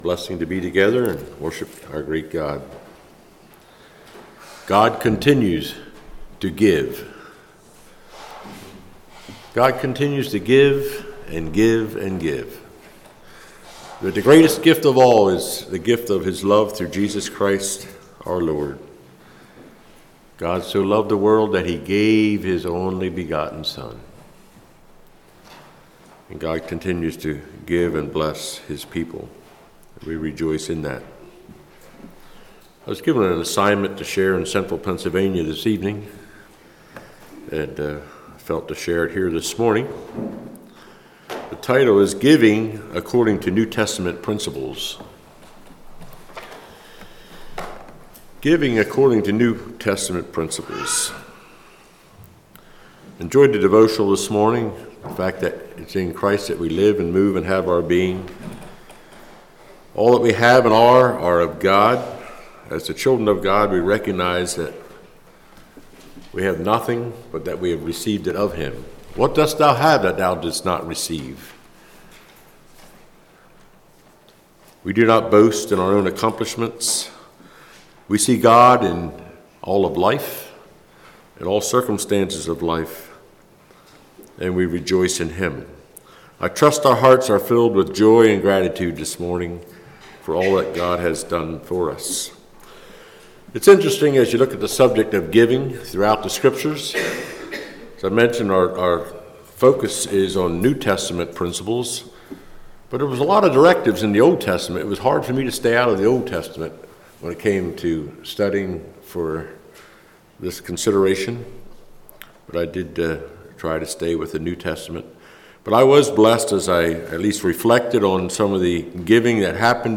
Congregation: Chapel